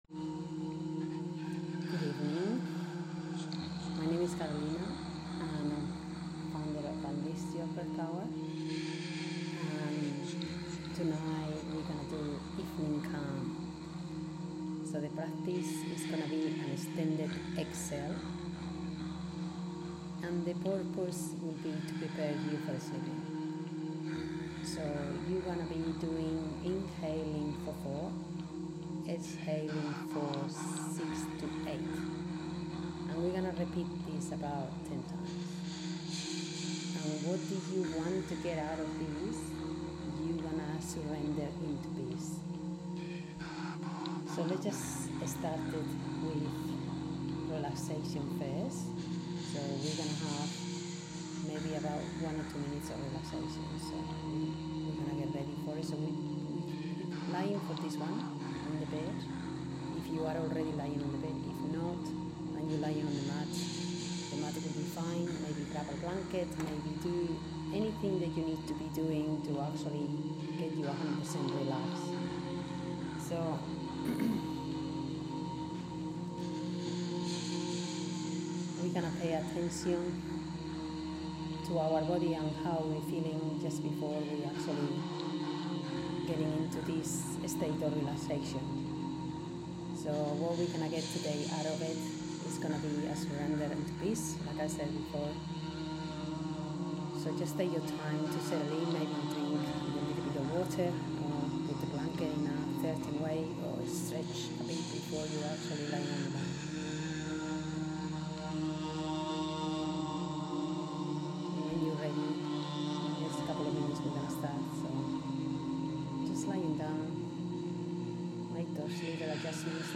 Evening Calm Guide BreathworkMP3 • 12229KB